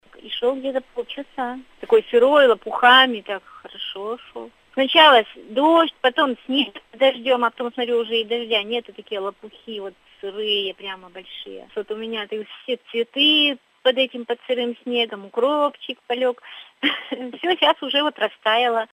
Вологжанка